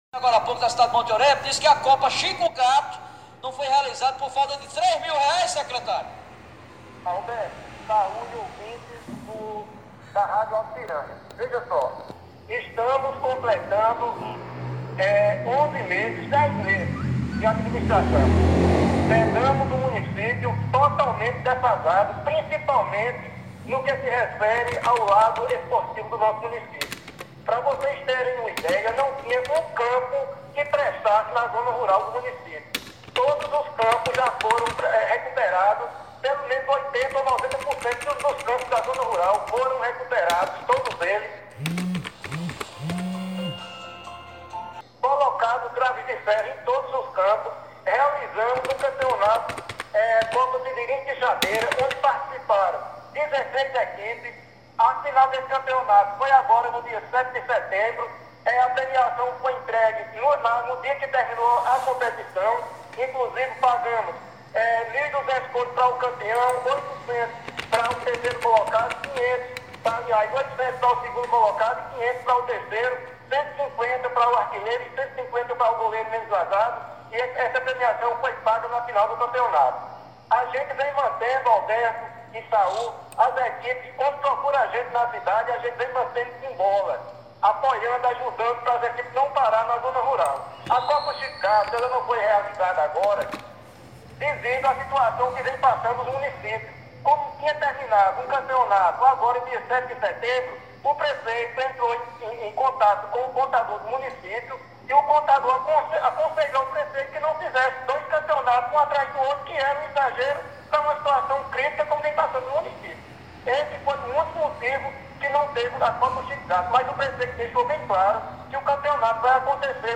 As informações repercutidas aqui pelo Radar Sertanejo foram veiculadas pela Rádio Alto Piranhas da cidade de Cajazeiras.